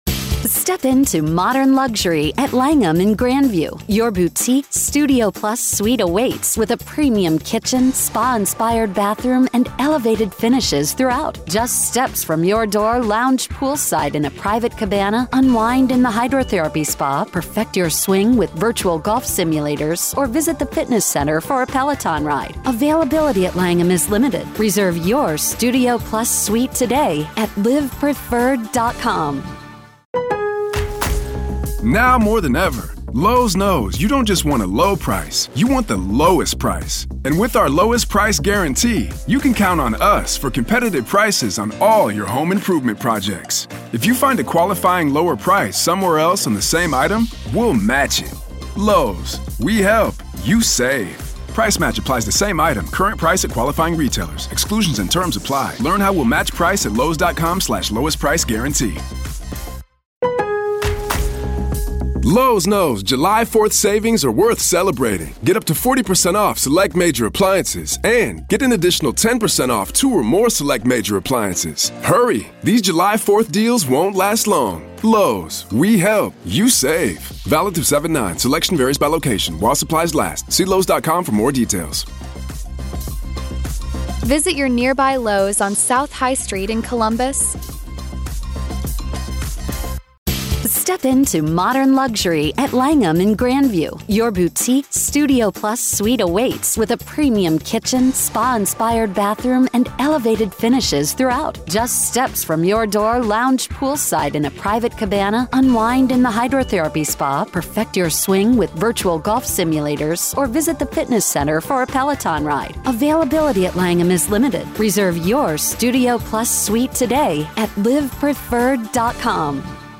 Former prosecutor